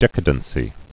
(dĕkə-dən-sē, dĭ-kādn-)